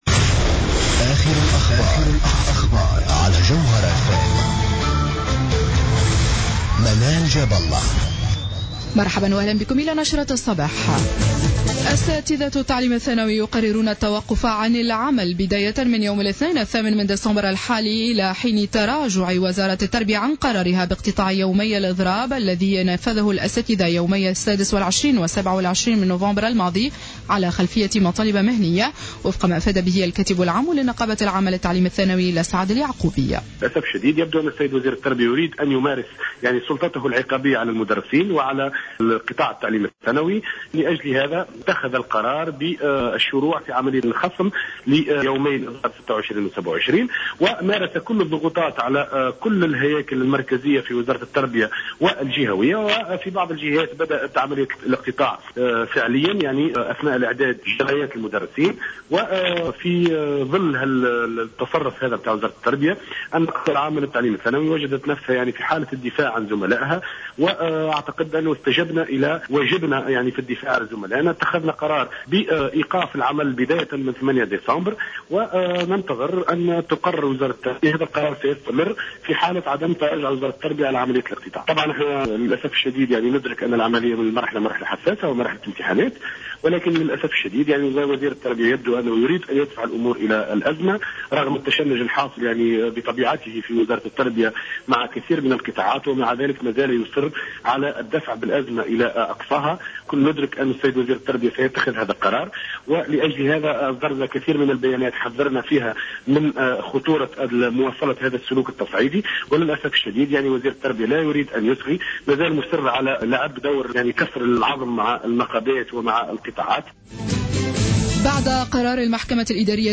نشرة أخبار السابعة صباحا ليوم السبت 06-12-14